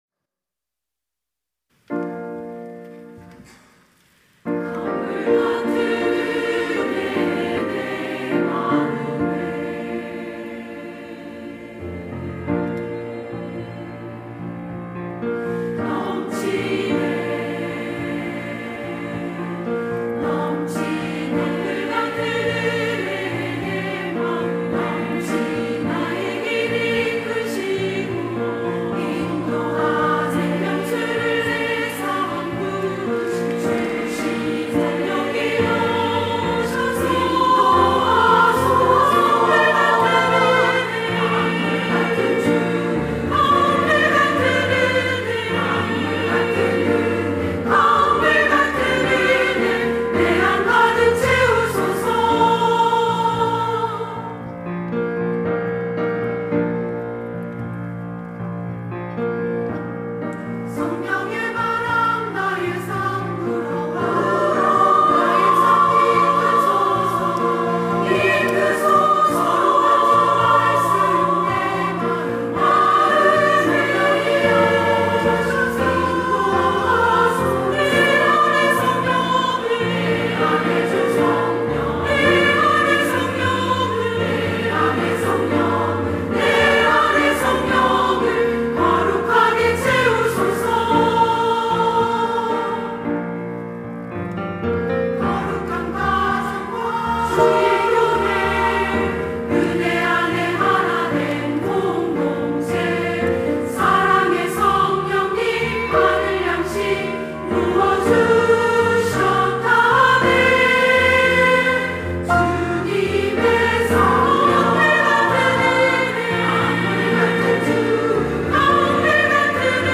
여전도회 - 강물 같은 은혜
찬양대 여전도회